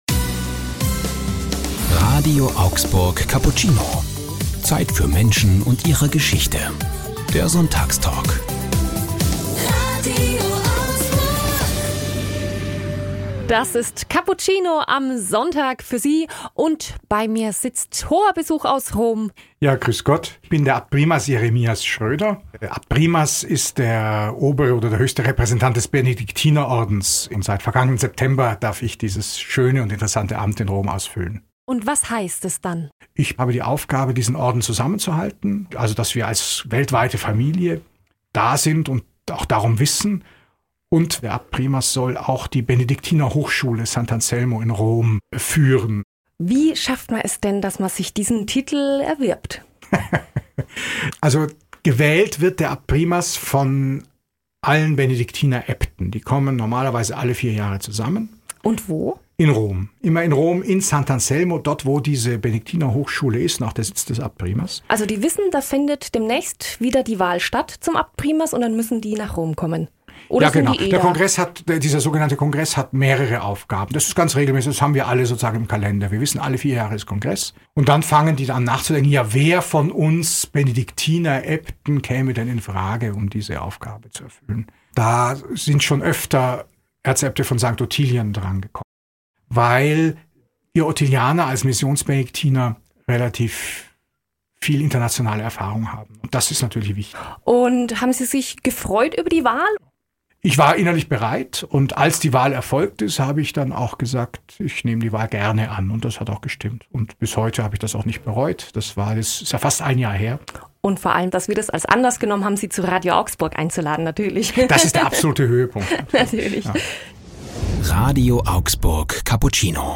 Von Mindelheim zum Oberhaupt der Benediktiner - Abtprimas Jeremias Schröder im Sonntagstalk ~ RADIO AUGSBURG Cappuccino Podcast
Wie es dazu kam, was ihn geprägt hat und wo er sich zu Hause fühlt, hier im Cappuccino-Interview.